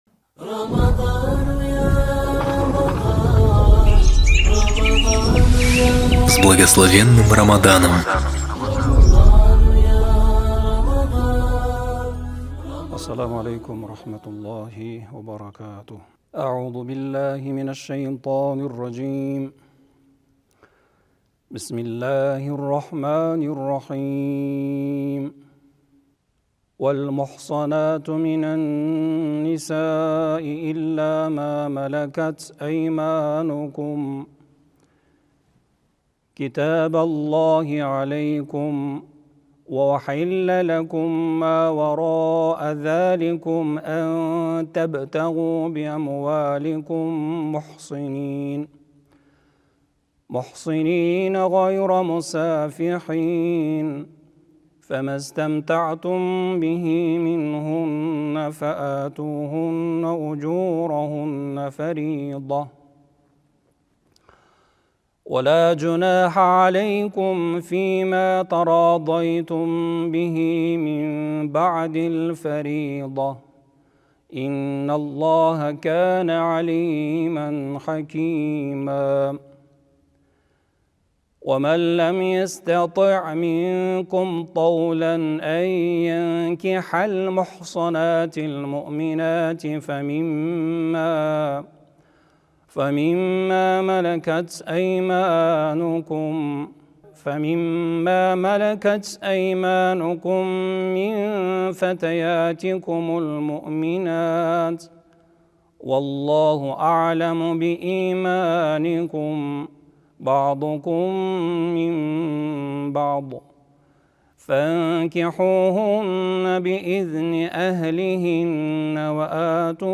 Хатм Корана читаемый в Центральной мечети г.Алматы в период Священного месяца Рамадан.